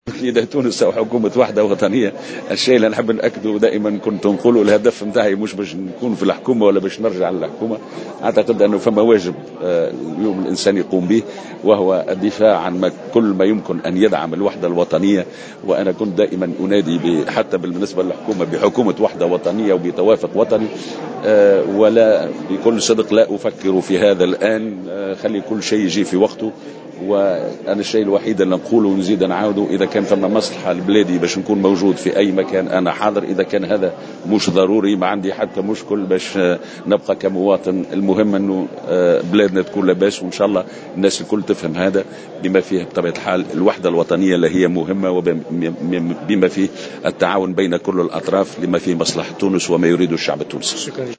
وأضاف مرجان في تصريح للجوهرة اف ام اليوم الاثنين على هامش حضوره في ندوة صحفية للمرشح الباجي قائد السبسي، أنه لا يفكر حاليا في تولي مسؤولية في الحكومة المقبلة، ولكن إن عرض عليه الأمر فإنه سيفكر في العرض من منطلق تحقيق مصلحة تونس مؤكدا أنه سيقبل في حال تحقق هذه المصلحة على حد تعبيره.